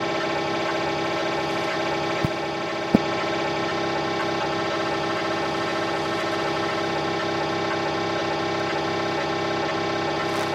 无线电噪音 " RadioNOIZE 2
描述：噪声收音机，从莫斯科的无线电扫描器Icom上录制的。
Tag: 调谐 弧度IO 噪音